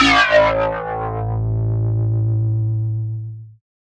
Analog Bass 1.wav